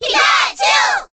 Category:Crowd cheers (SSBB) You cannot overwrite this file.
Pikachu_Cheer_Korean_SSBB.ogg.mp3